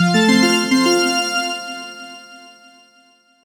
Victory/Defeat jingle